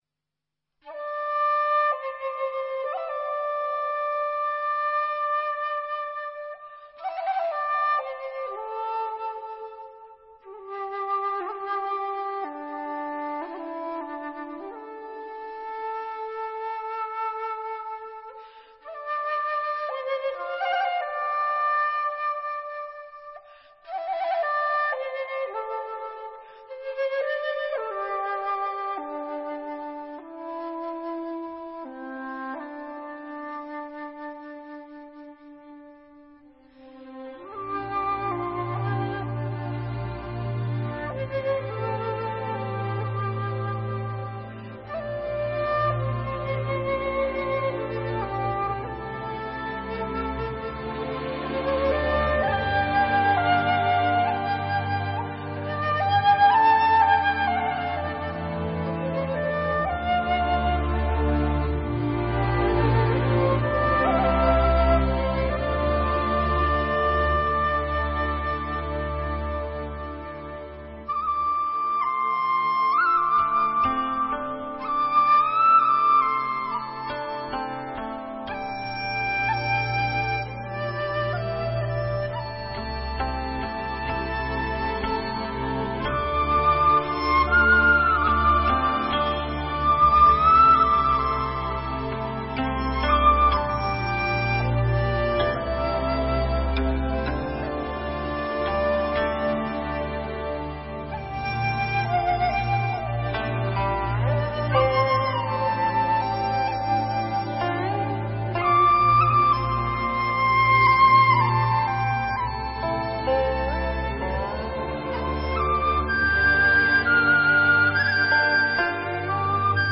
本作品动态超大,声场宽阔请谨慎调节音量,避免损坏喇叭